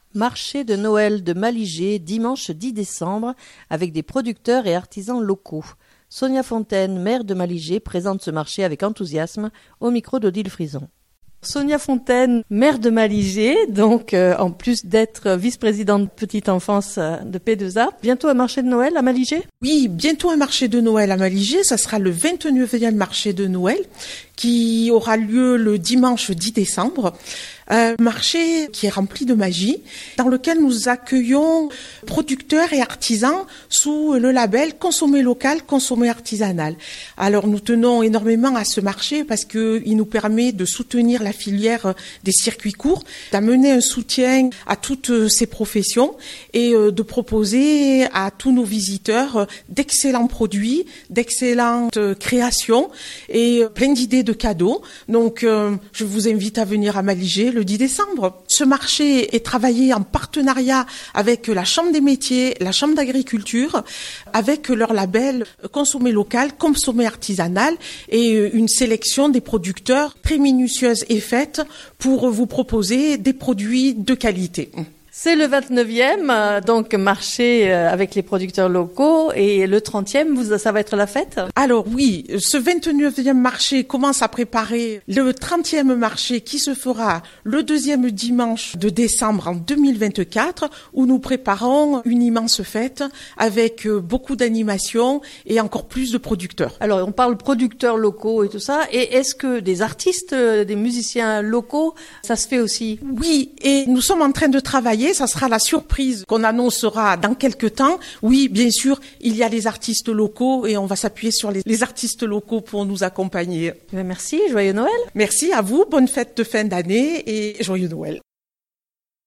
Sonia Fontaine Maire de Malijai présente ce marché avec enthousiasme